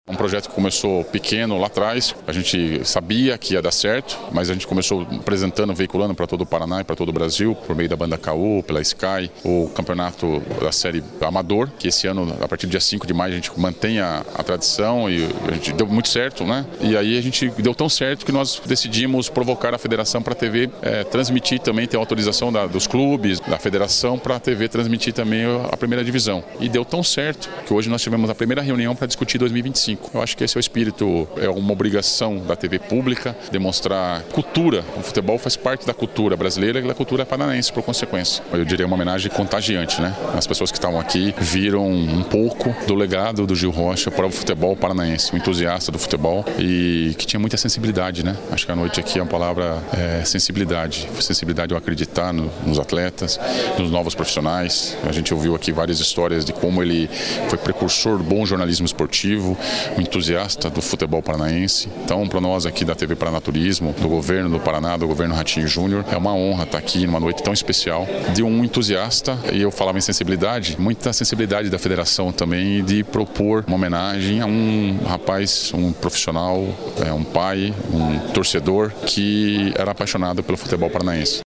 Sonora do secretário da Comunicação, Cleber Mata